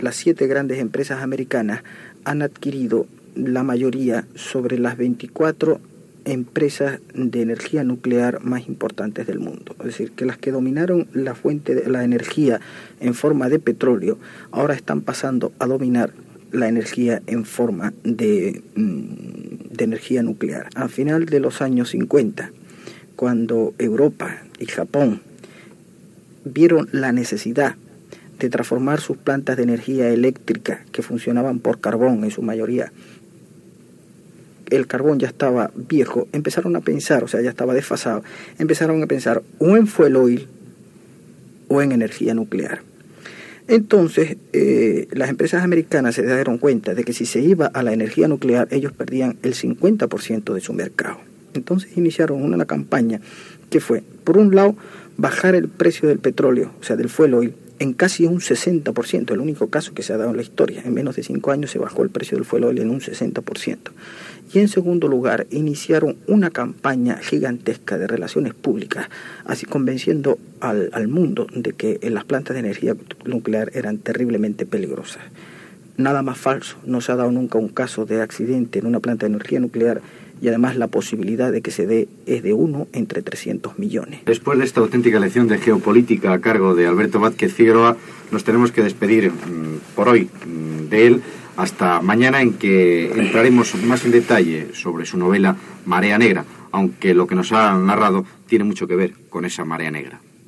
Alberto Vázquez Figueroa presenta la seva novel·la "Marea Negra" dedicada a les indústries energètiques
Extret del programa "Audios para recordar" de Radio 5 en el portal RNE Audio